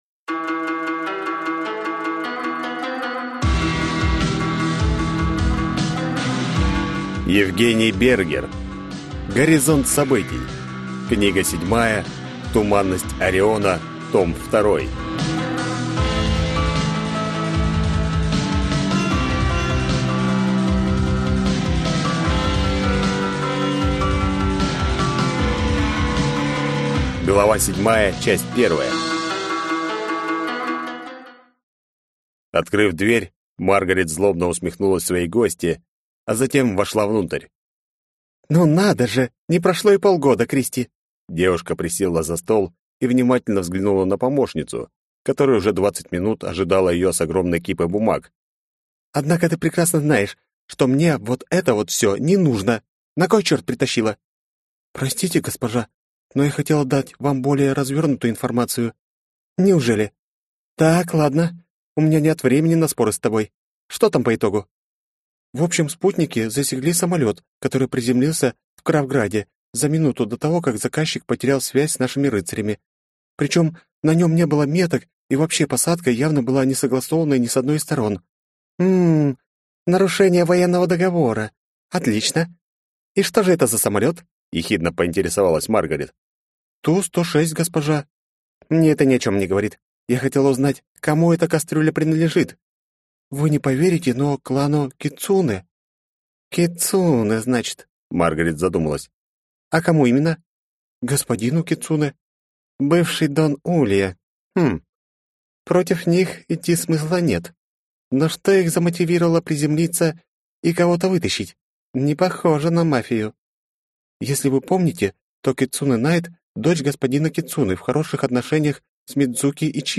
Аудиокнига Горизонт событий. Книга 7. Туманность Ориона. Том 2 | Библиотека аудиокниг